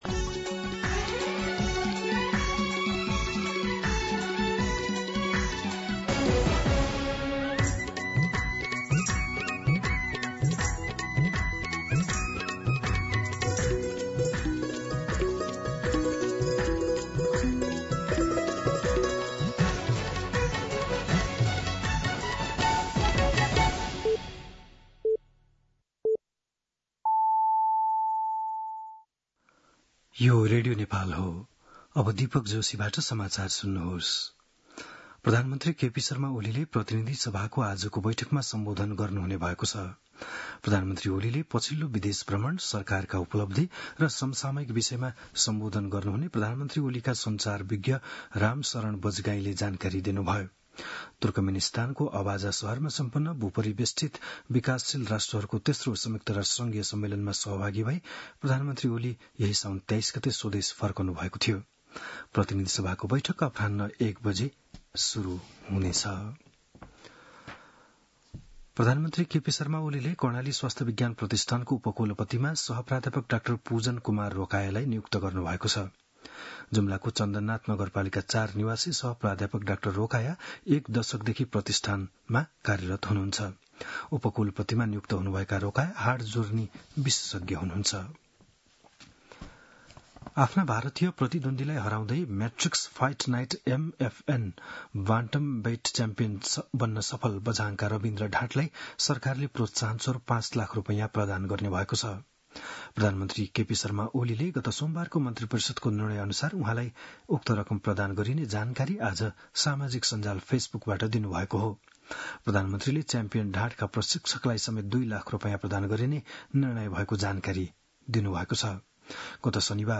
बिहान ११ बजेको नेपाली समाचार : २८ साउन , २०८२